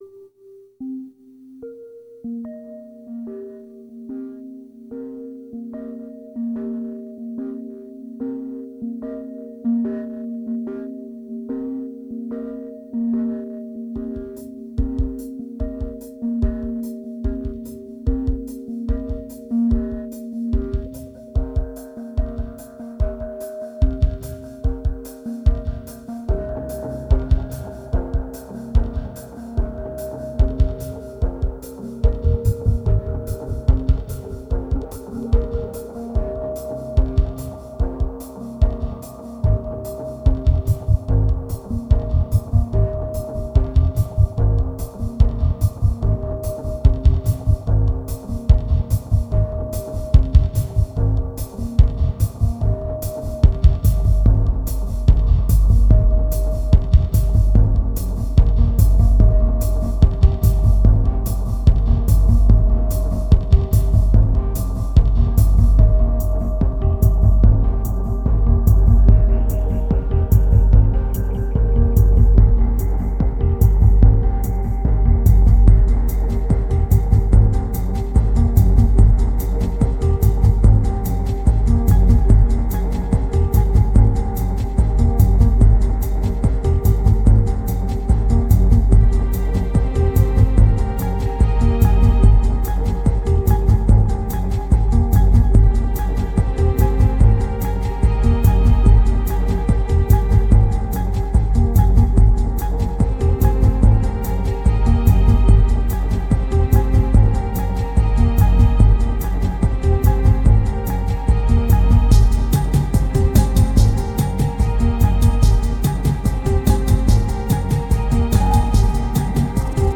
2123📈 - -51%🤔 - 73BPM🔊 - 2010-12-04📅 - -330🌟